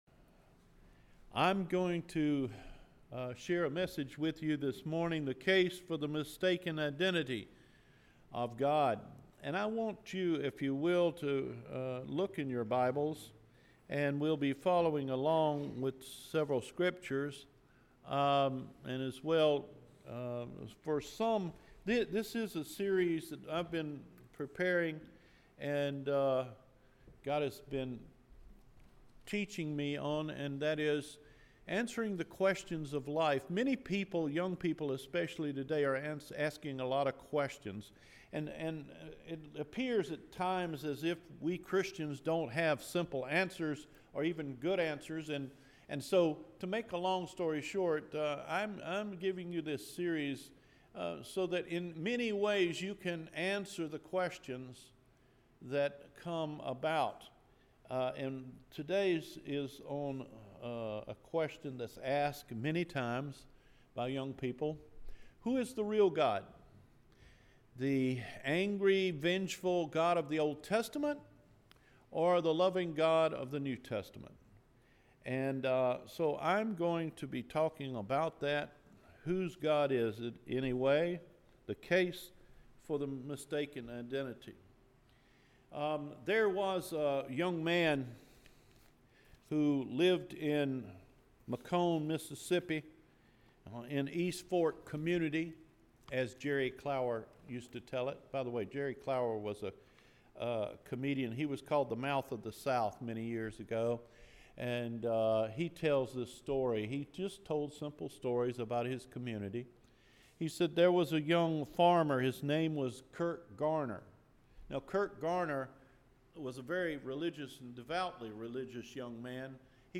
Who is the Real God? June 10 Sermon